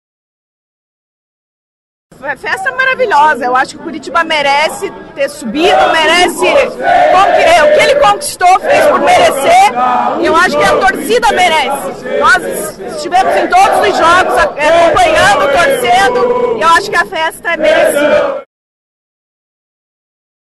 A chegada da equipe transformou o saguão em um corredor verde e branco, com cantos, bandeiras e muita comemoração pela conquista da Série B do Campeonato Brasileiro.